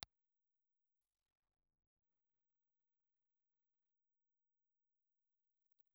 Impulse Response file of RSA RL1 ribbon microphone.
In terms of sound, these have a very vintage tone and limited bandwidth, rolling off rapidly above around 4kHz – perfect for an old AM radio broadcast effect.